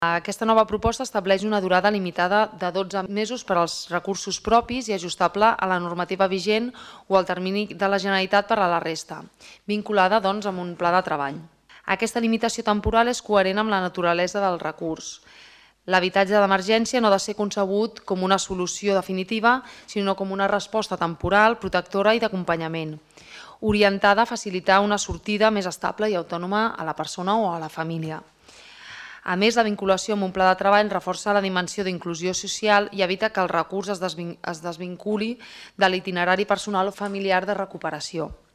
Cristina Dalmau, regidora Benestar Social